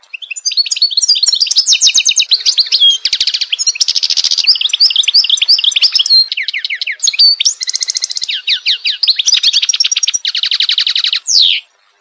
mixed-birds-sounds.mp3